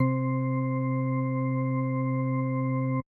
Organ - Church.wav